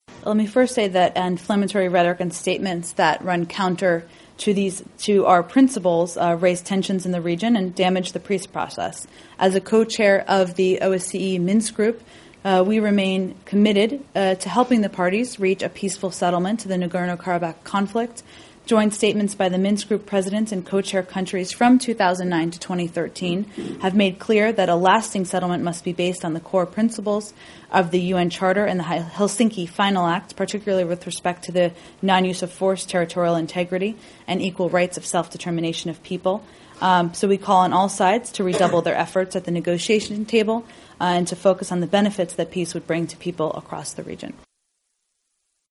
ABŞ Dövlət katibinin sözçüsü Cen Psaki Azərbaycan prezidenti İlham Əliyevin Respublika günü münasibətilə çıxışı ilə bağlı açıqlama verib